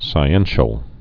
(sī-ĕnshəl)